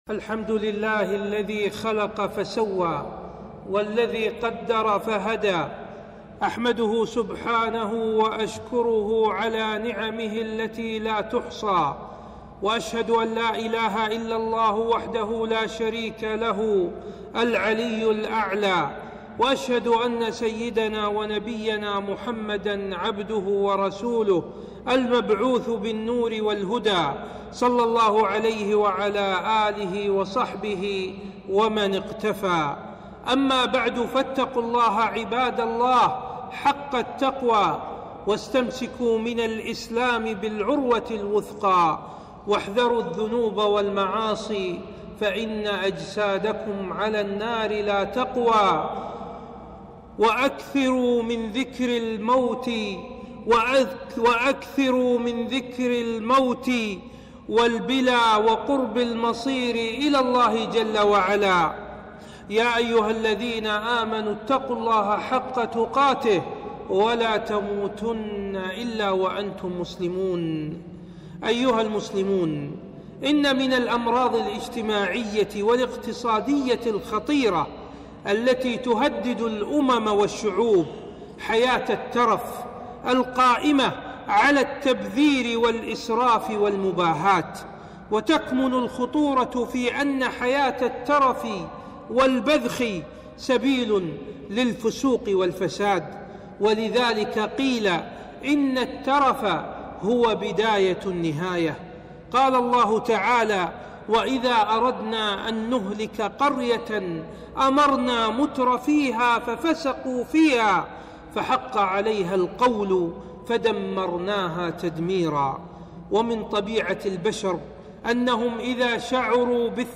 خطبة - الاسراف والمباهاة في الحفلات الولائم